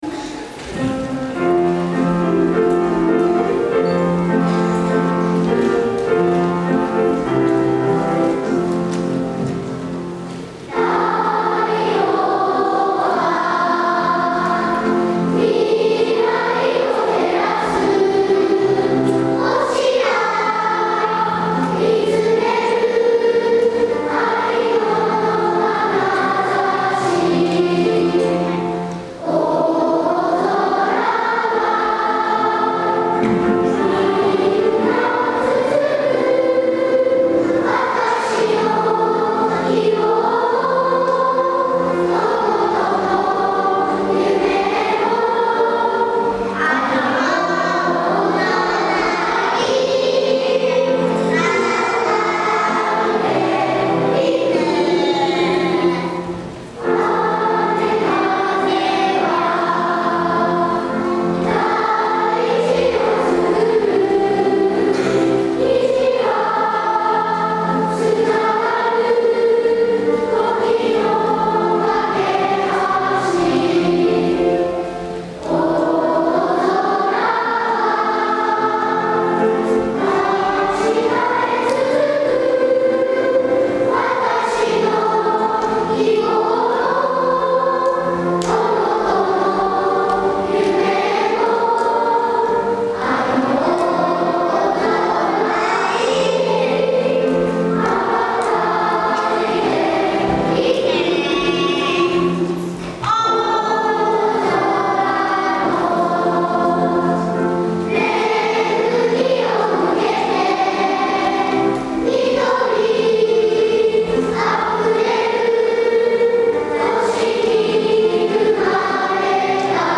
校歌」会場２部合唱です。